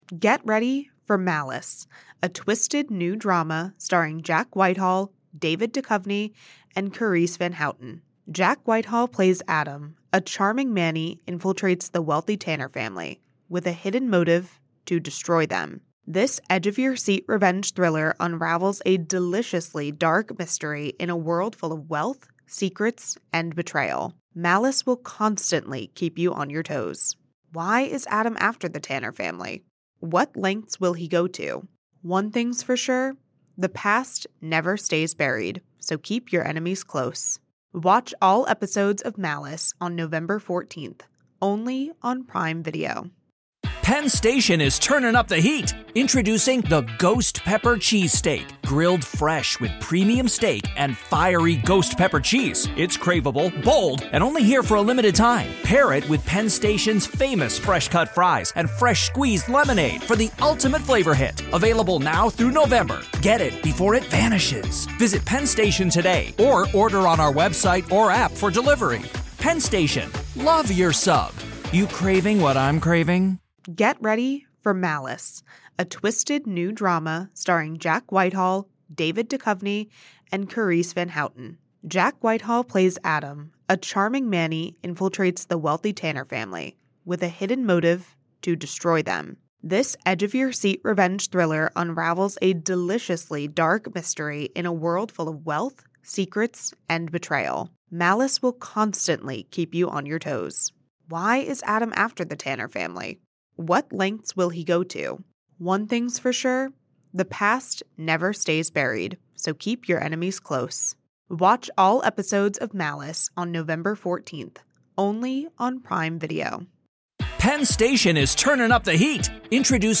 Tune in for a riveting conversation with one of the most seasoned experts in the field of personal security and protective operations.